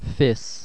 I